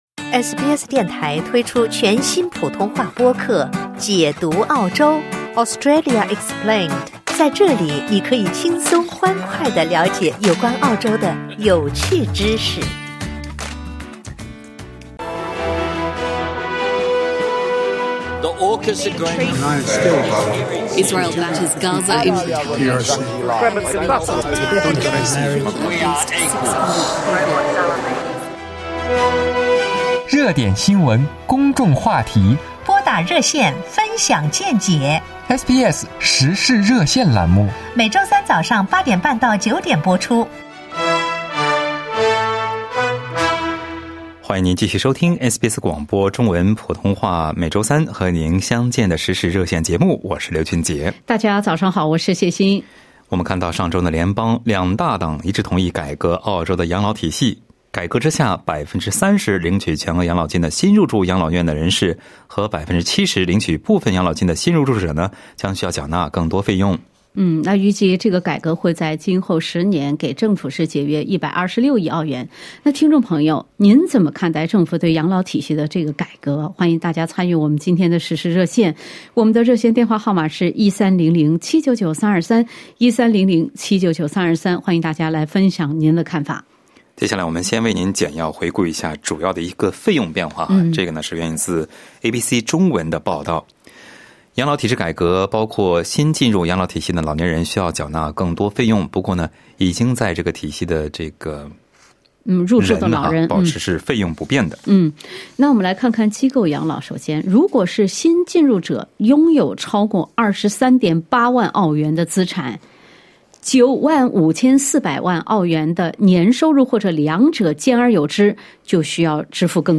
在本期《时事热线》节目中，有些听友质疑改革的公平性，认为是“劫富济贫”，也有听友认为，改革可以改善养老业的可持续性。